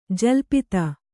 ♪ jalpita